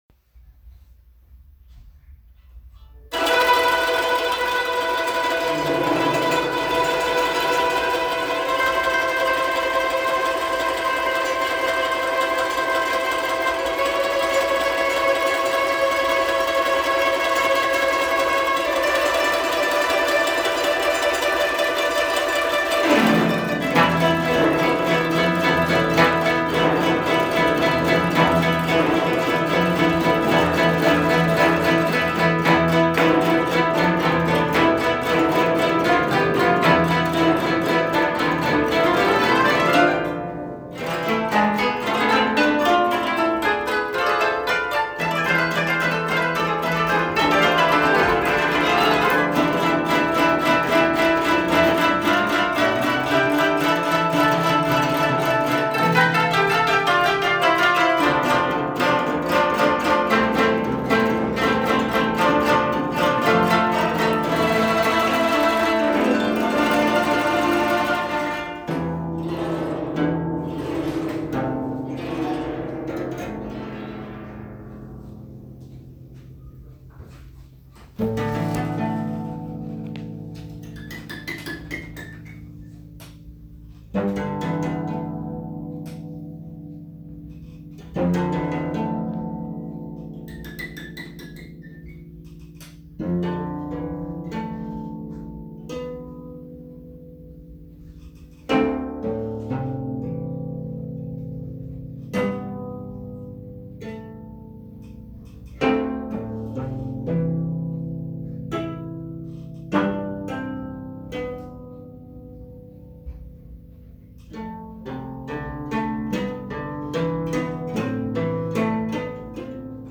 「龍星群」 伝統文化部箏メンバー演奏
→→「龍星群」 演奏：伝統文化部３年箏メンバー